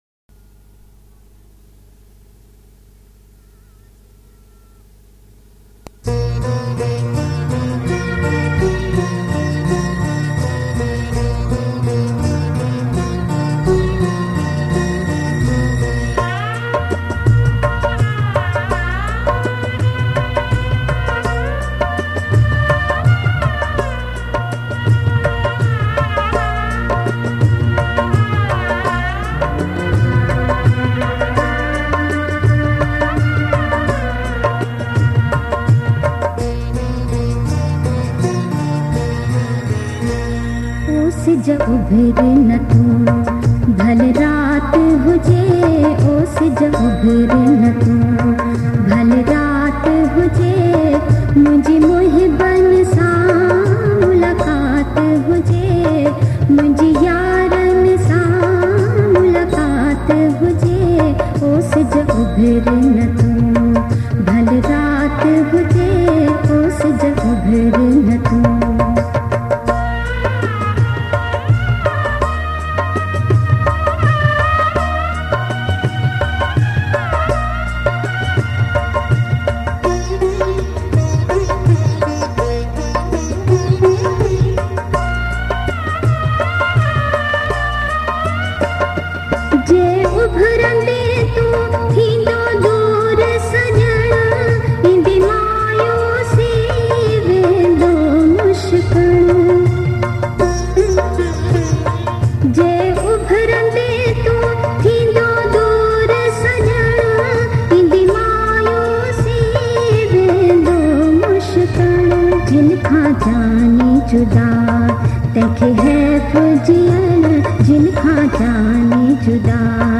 Sindhi Geet ain Kalam. Classical songs